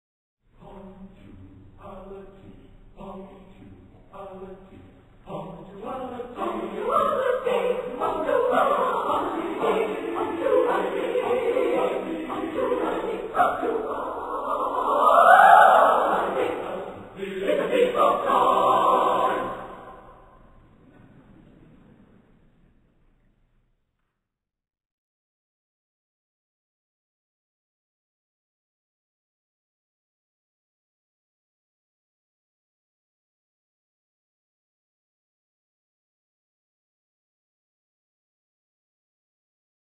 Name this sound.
Voicing: SATB divisi